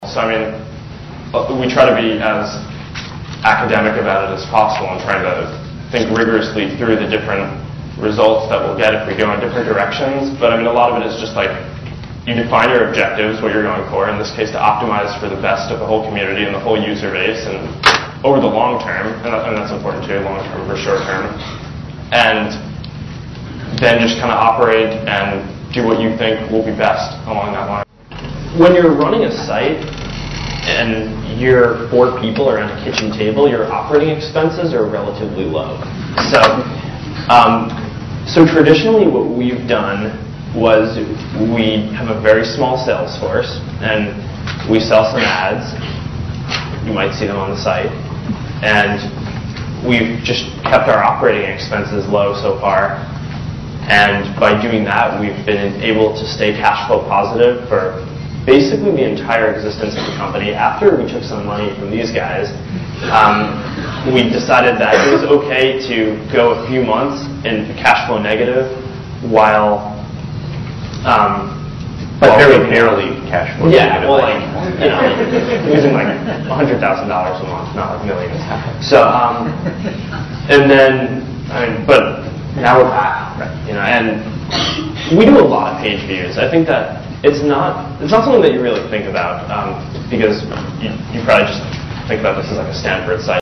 这些财富精英大多是世界著名公司的CEO，在经济领域成就斐然。在演讲中他们或讲述其奋斗历程，分享其成功的经验，教人执着于梦想和追求；或阐释他们对于公司及行业前景的独到见解，给人以启迪和思考。